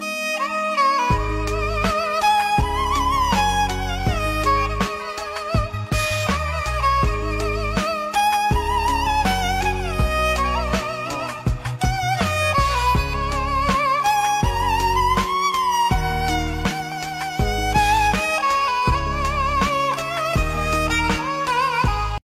Giai điệu TikTok gây bão với tiếng đàn cò ma mị, cuốn hút.